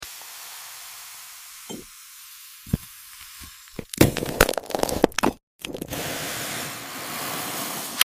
Cutting Glass SOUNDS You Can sound effects free download
Cutting Glass SOUNDS You Can Feel 🔪✨ | ASMR Satisfaction